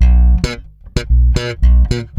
-AL DISCO C.wav